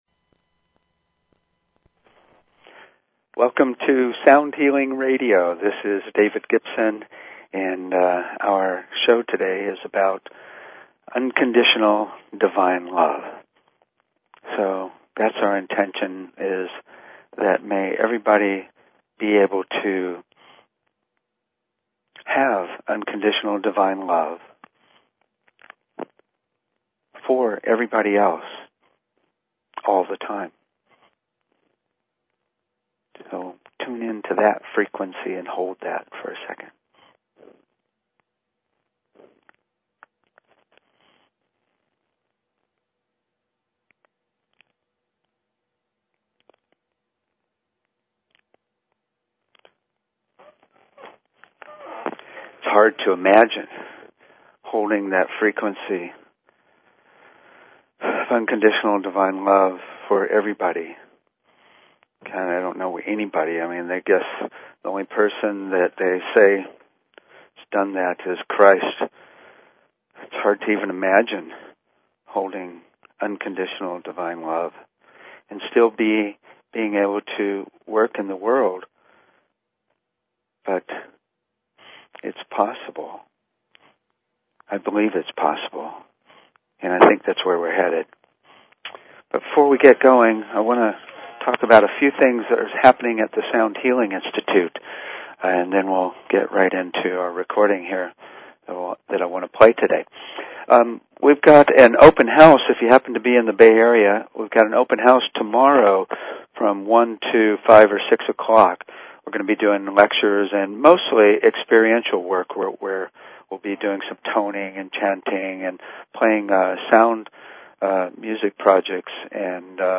Talk Show Episode, Audio Podcast, Sound_Healing and Courtesy of BBS Radio on , show guests , about , categorized as
The show is a sound combination of discussion and experience including the following topics: Toning, Chanting and Overtone Singing - Root Frequency Entrainment - Sound to Improve Learning -Disabilities - Using Sound to Connect to Spirit - Tuning Fork Treatments - Voice Analysis Technologies - Chakra Balancing - Sound to Induce Desired S
We will explore the Sound of Unconditional Divine Love and it's relationship to Compassion. We'll then play our new 30 minute recording of Unconditional Divine Love and use our intention to bring in and resonate it's frequency.